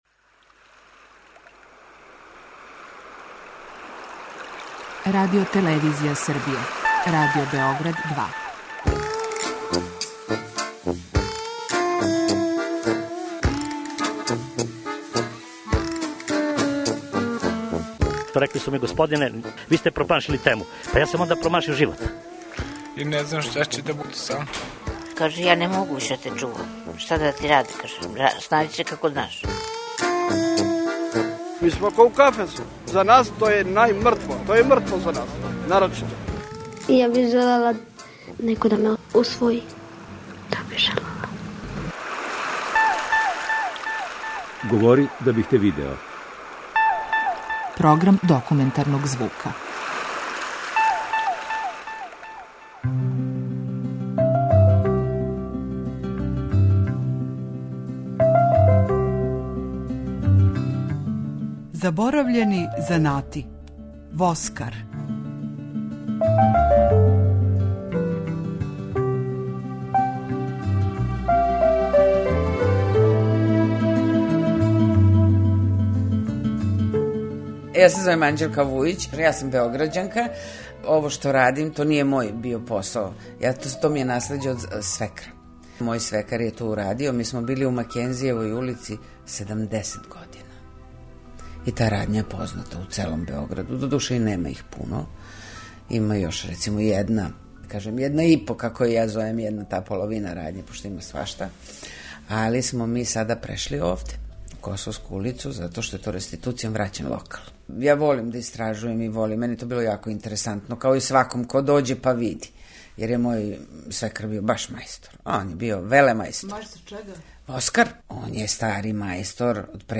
Документарни програм: Заборављени занати - воскар